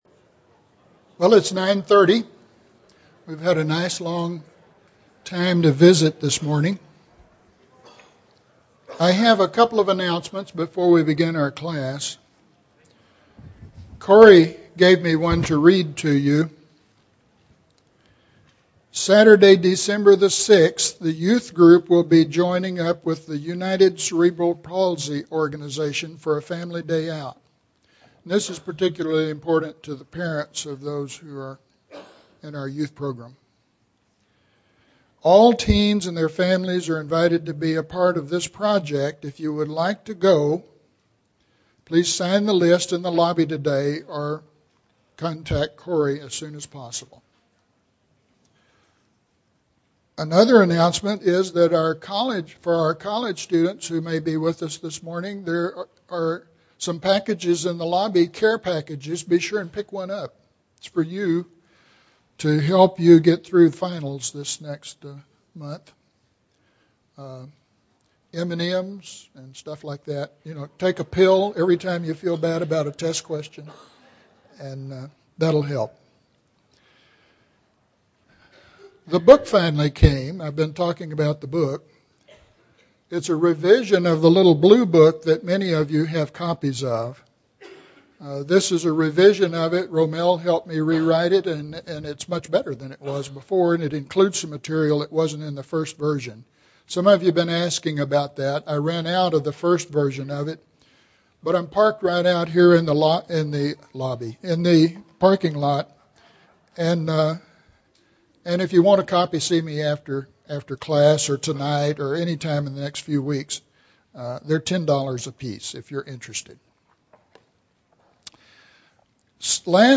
The Flood Story in Revelation Chapters 4-7 (8 of 10) – Bible Lesson Recording